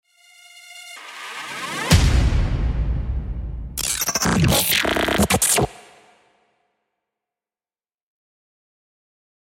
Electro Glitch Vibes
Sci-Fi / Future